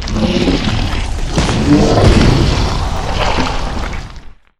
Index of /client_files/Data/sound/monster/dx1/